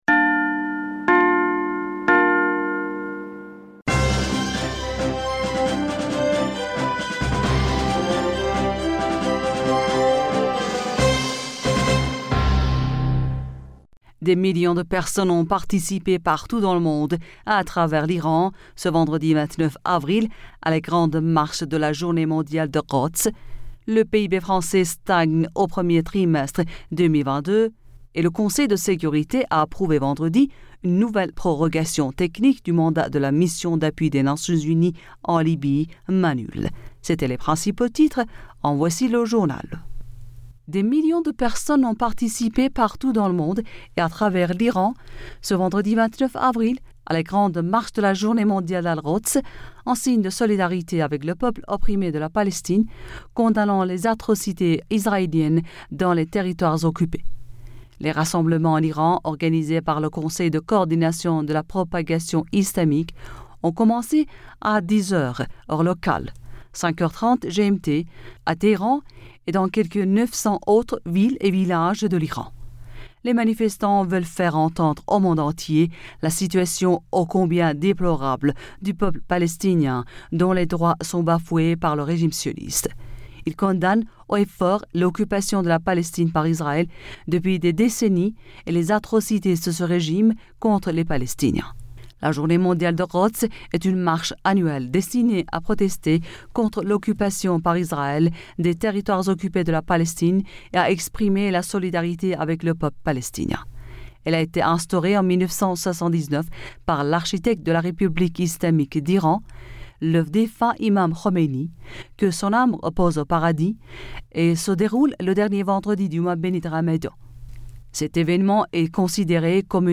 Bulletin d'information Du 30 Mai 2022